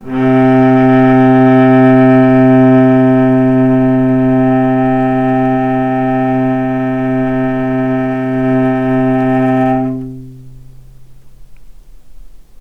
healing-soundscapes/Sound Banks/HSS_OP_Pack/Strings/cello/ord/vc-C3-mf.AIF at ae2f2fe41e2fc4dd57af0702df0fa403f34382e7
vc-C3-mf.AIF